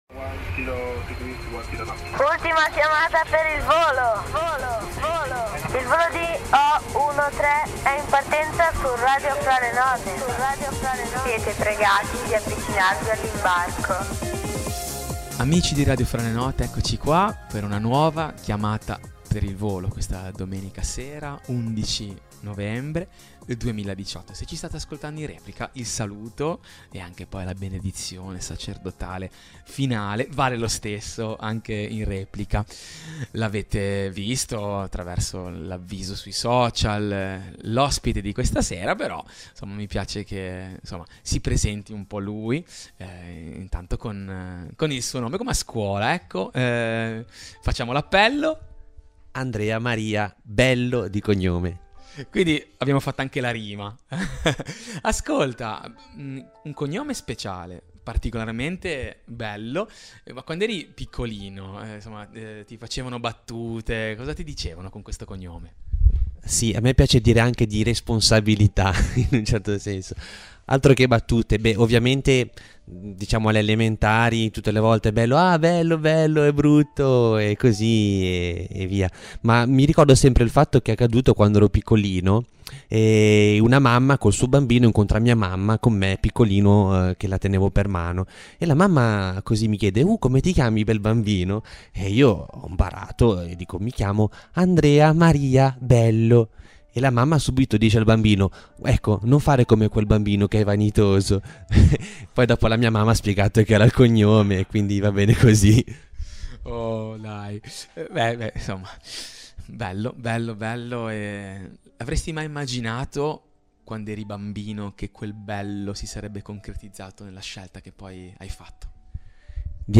In onda la domenica sera alle 21:00 in diretta dalla Sede centrale di Radio Fra le note in Via Minoretti di Genova.